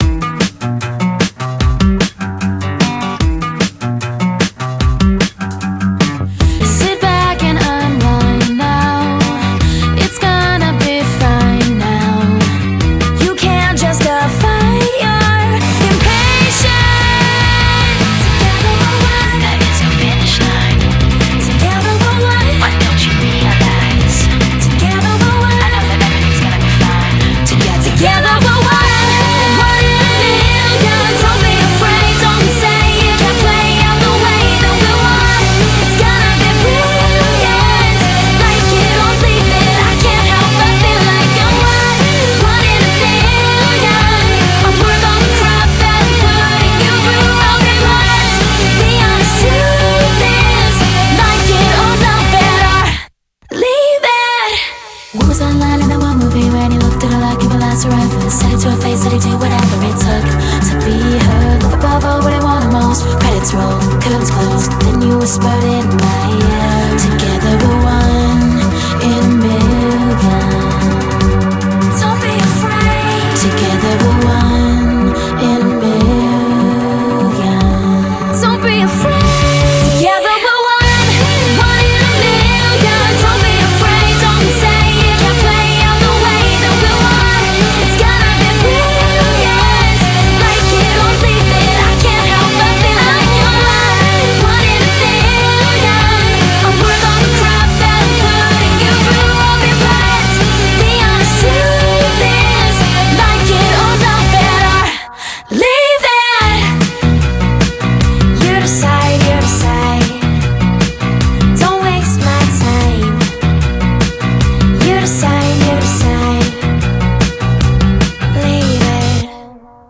BPM150